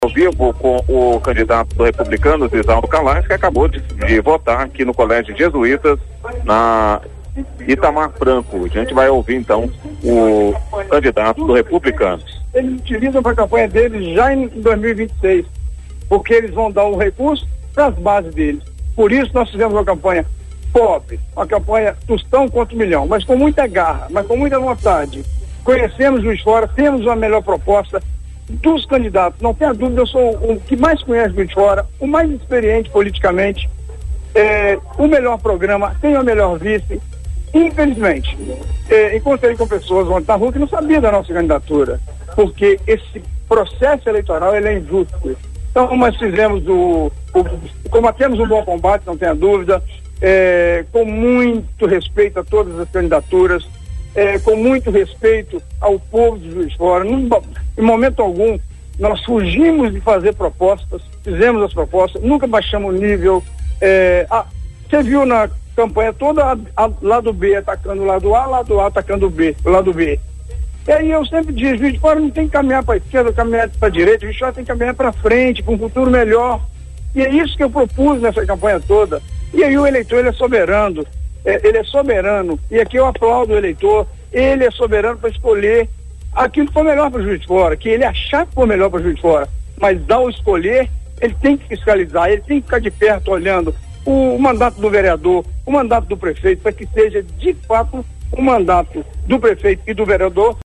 Após votar, Isauro Calais conversou com a imprensa sobre suas propostas de campanha e sua candidatura.